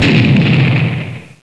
explode4.wav